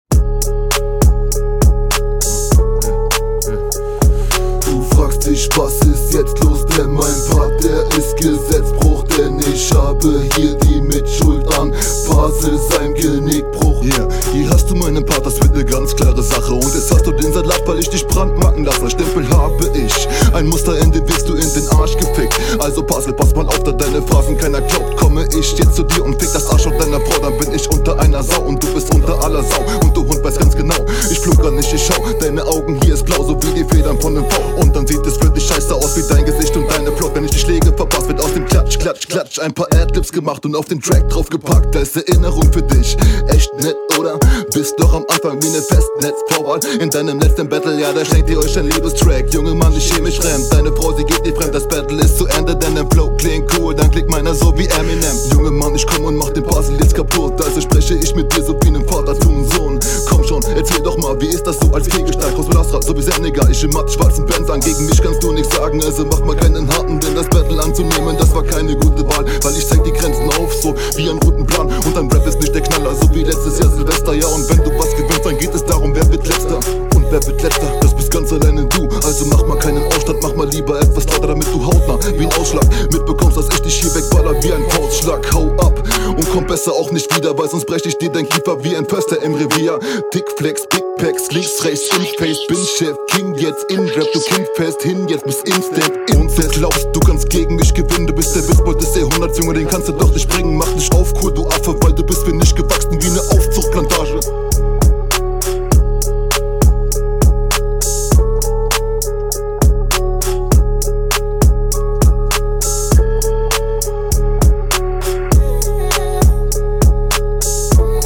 Battle Rap Bunker
Der Sound kommt fett und wuchtig rüber, wie es in einem Battle sein sollte.